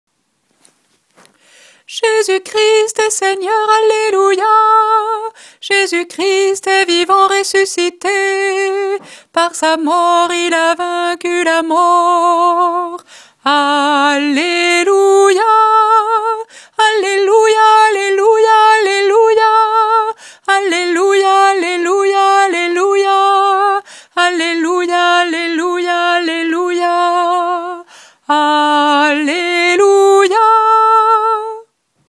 Tradition polonaise
Voix chantée (MP3)COUPLET/REFRAIN
SOPRANE